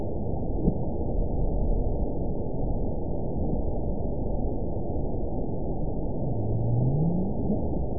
event 918052 date 04/28/23 time 16:18:00 GMT (2 years, 1 month ago) score 9.46 location TSS-AB05 detected by nrw target species NRW annotations +NRW Spectrogram: Frequency (kHz) vs. Time (s) audio not available .wav